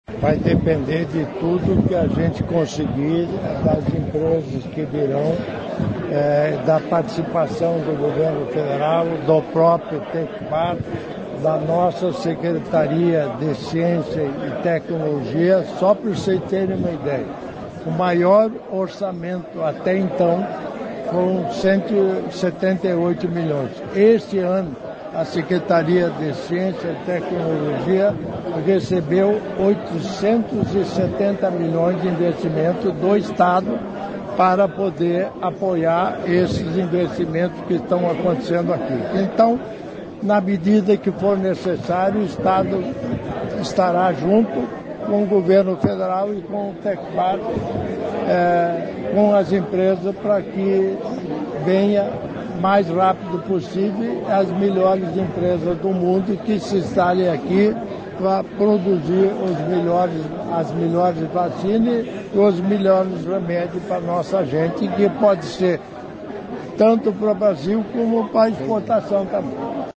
O vice-governador Darci Piana comentou o investimento do Estado na obra.